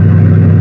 engine2.wav